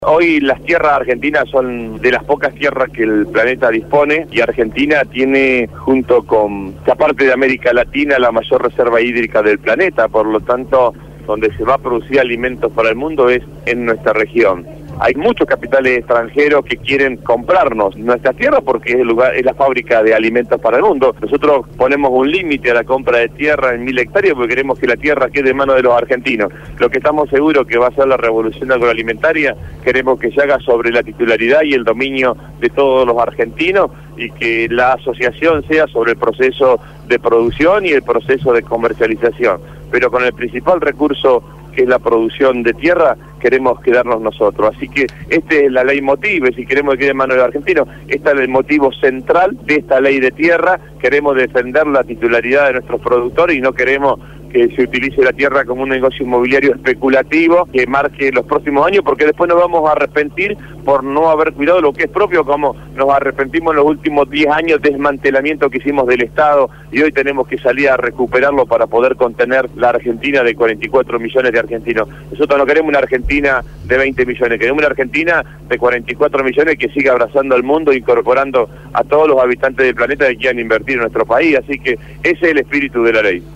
El Ministro de Agricultura de la Nación y candidato a Diputado Nacional por la Provincia de Buenos Aires acompañó a Cristina Fernandez en la Fiesta del Maíz en Chacabuco.